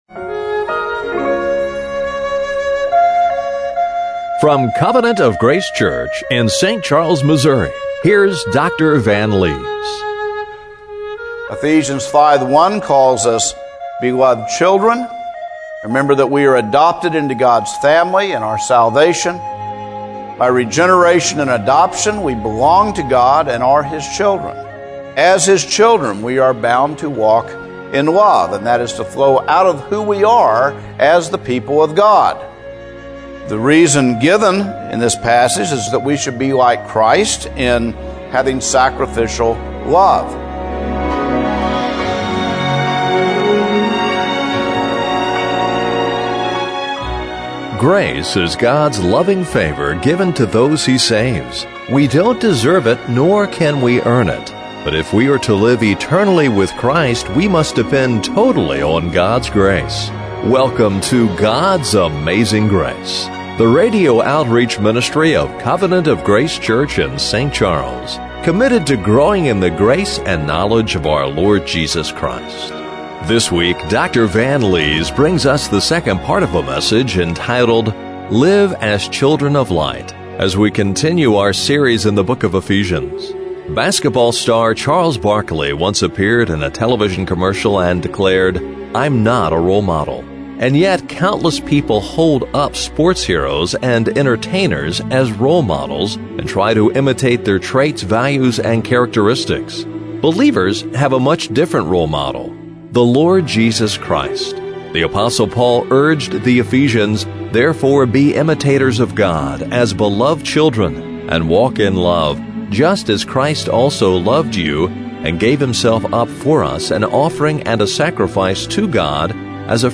Ephesians 5:1-12 Service Type: Radio Broadcast How can we learn to imitate the Lord as we seek to walk as children of light?